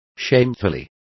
Complete with pronunciation of the translation of shamefully.